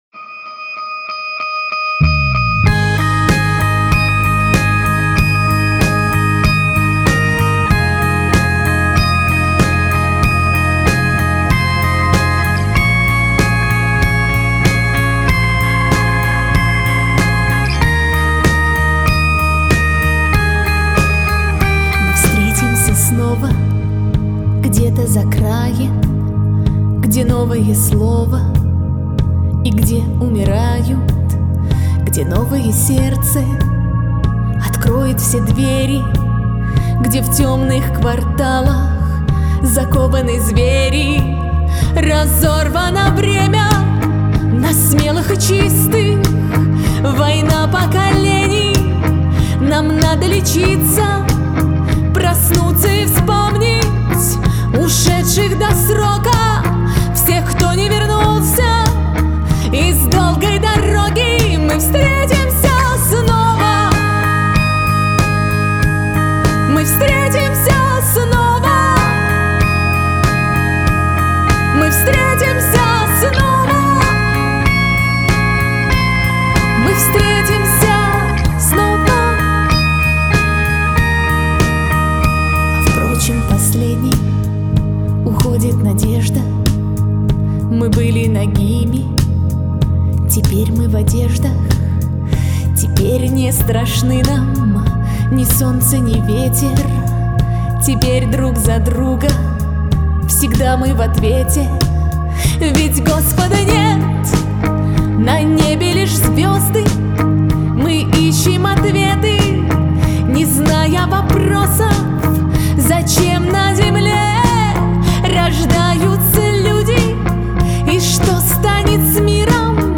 вокал
гитара
бас
барабаны